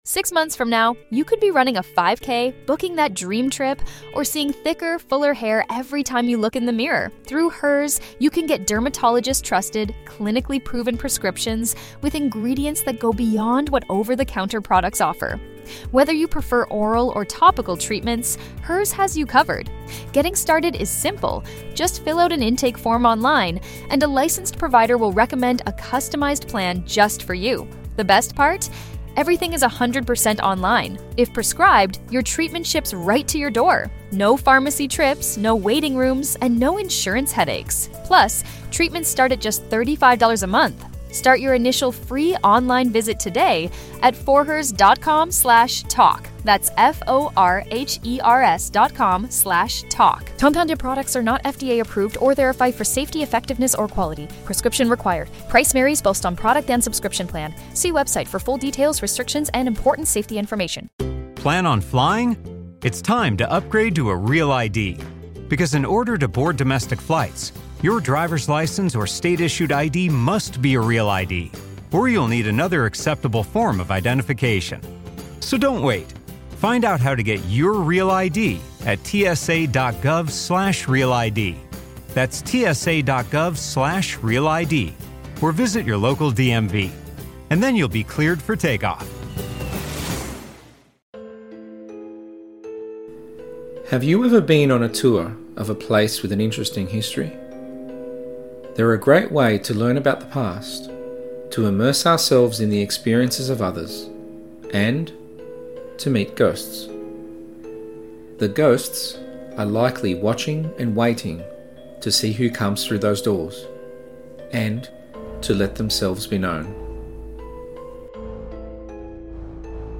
I have collected REAL stories from real people over the years about their experiences and with permission I am retelling them to you.